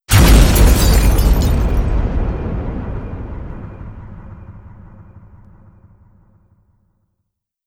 sunken.wav